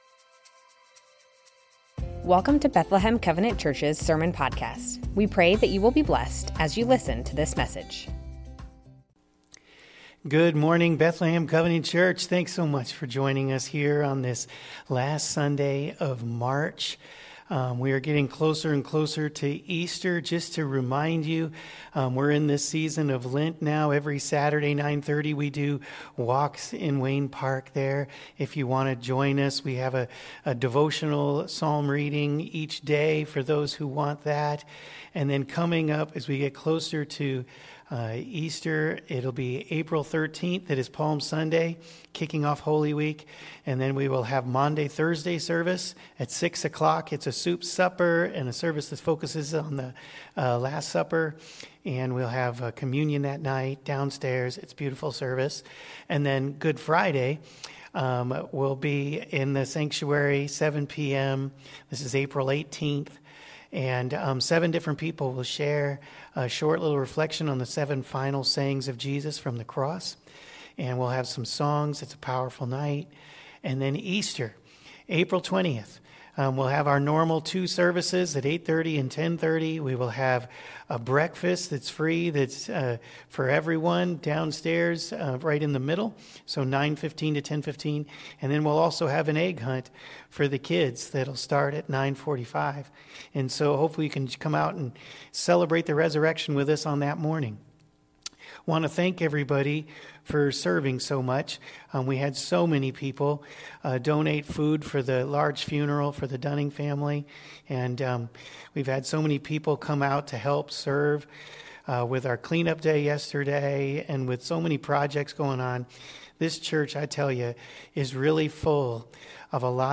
Bethlehem Covenant Church Sermons Mark 4:35-5:25 - Jesus calms storms Mar 30 2025 | 00:36:31 1x 00:00 / 00:36:31 Subscribe Share Spotify RSS Feed Share Link Embed